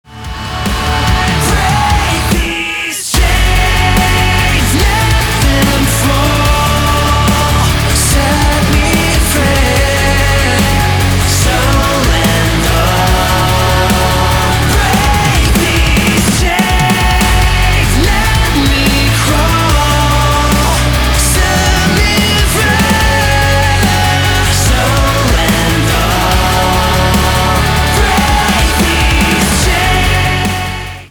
альтернатива
гитара , барабаны , качающие , крутые , грустные , крики